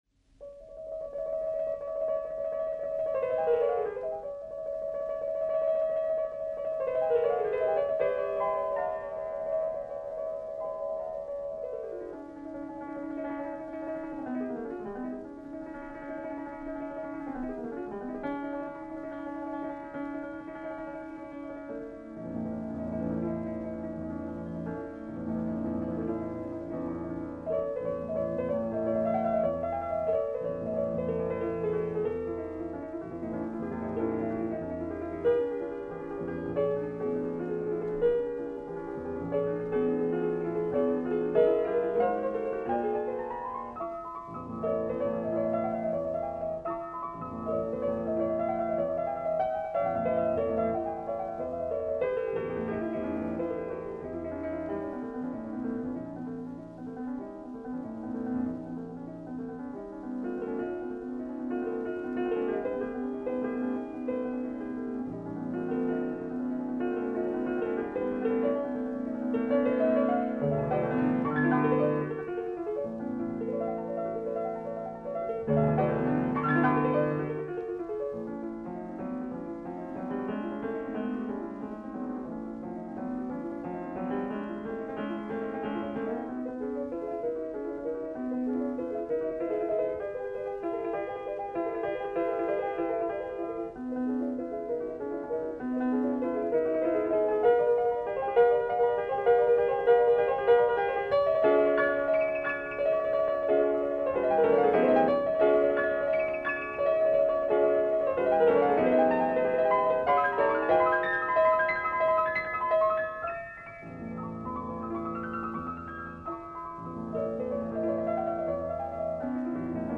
Debussy's L'Isle Joyeuse: Piano Masterpiece of Joy and Imagination
Debussy opens with trills and a cadenza, but it is not that which sets the key: the use of the whole-tone scale has an earlier precedent from its use in the ‘darker moments in Pelléas et Mélisande’.
The work progresses from a small, localised trill to a final gesture, much in the manner of Liszt, that encompasses the whole keyboard. It’s pure imagination and happiness, yet with a bit of a twinge.